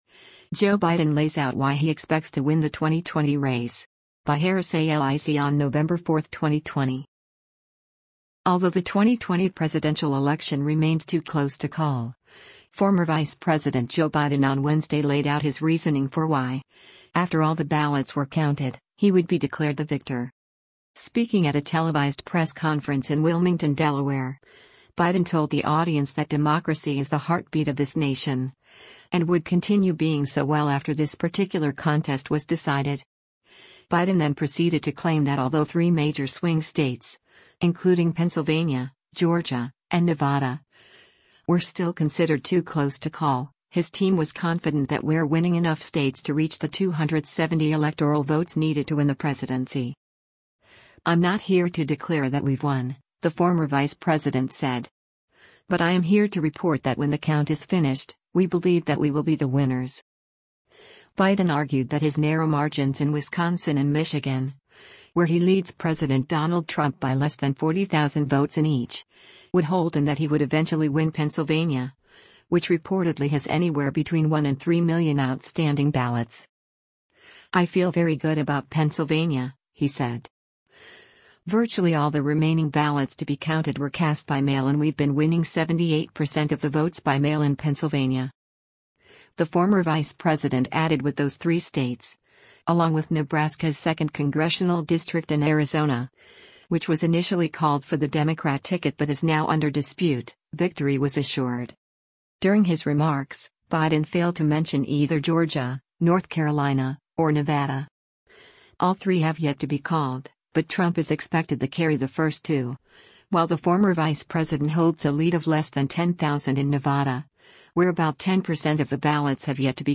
Speaking at a televised press conference in Wilmington, Delaware, Biden told the audience that “democracy is the heartbeat of this nation,” and would continue being so well after this particular contest was decided.